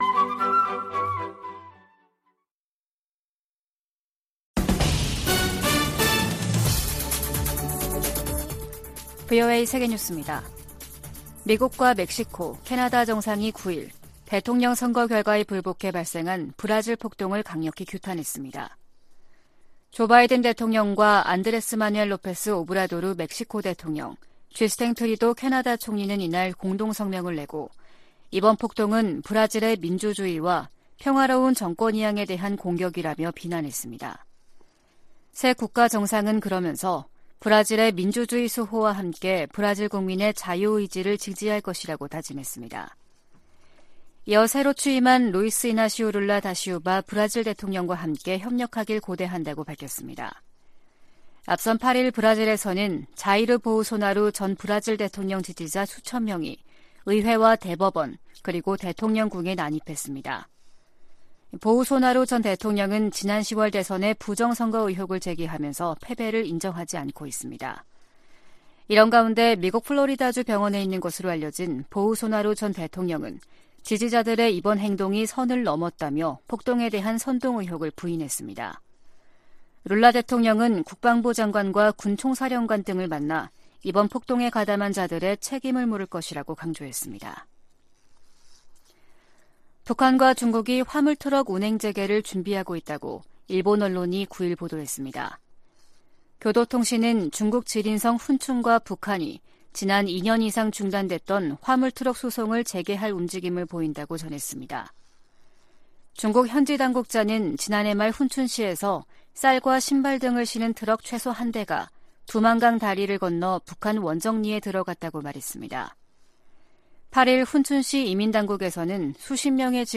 VOA 한국어 아침 뉴스 프로그램 '워싱턴 뉴스 광장' 2023년 1월 10일 방송입니다. 미 국무부는 6년째 공석인 북한인권특사 임명 여부와 관계 없이 미국은 북한 인권 문제에 집중하고 있다고 밝혔습니다. 미국의 한반도 전문가들은 실효성 논란에 휩싸인 9.19 남북 군사합의와 관련해, 이를 폐기하기보다 북한의 도발에 대응한 ‘비례적 운용’이 더 효과적이라고 제안했습니다.